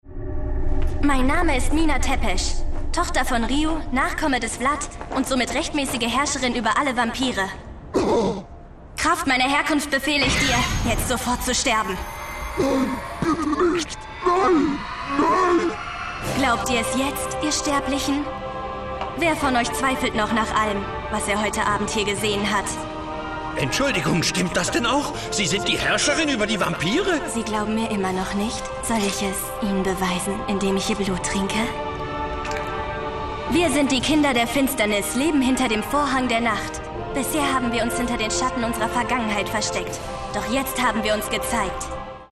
junge aufstrebende Sprecherin
Sprechprobe: Sonstiges (Muttersprache):